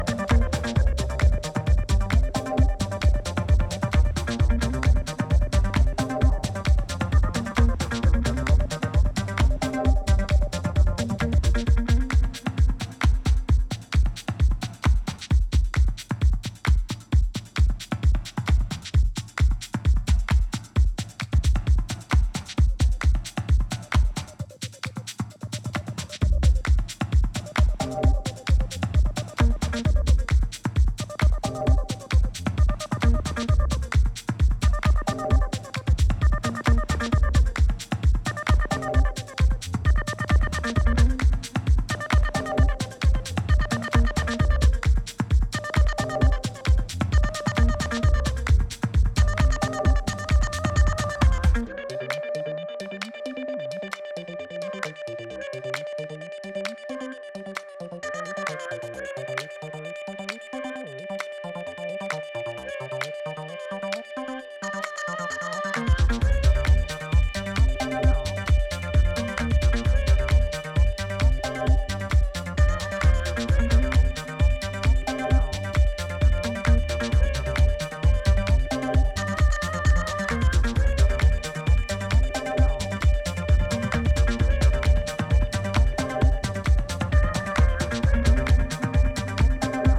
同じくジャジーなマナーをボトムに残しながらアシッドラインと陶酔的なヴォイス・サンプルを配した